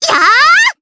Rehartna-Vox_Attack4_jp.wav